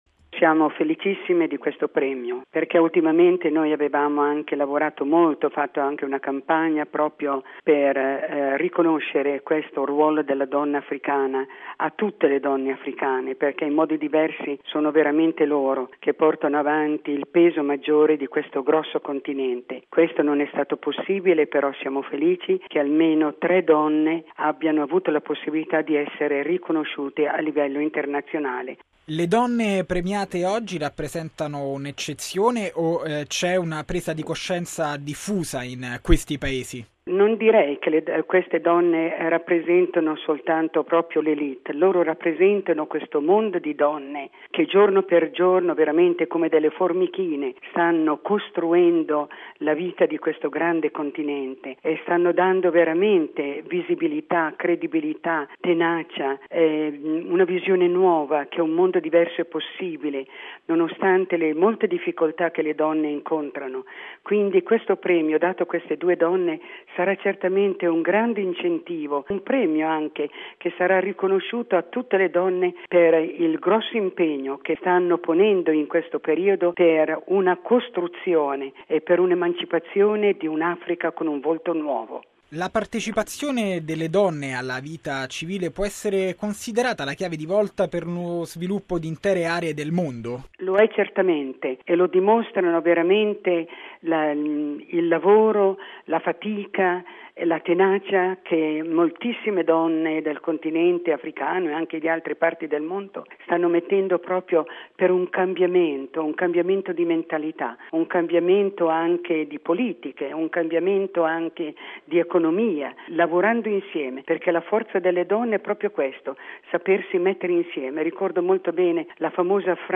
Gioia per l’assegnazione del premio Nobel per la pace a tre donne è stata espressa dal cardinale Peter Kodwo Appiah Turkson, presidente del Pontificio Consiglio della Giustizia e della Pace. Questa la sua dichiarazione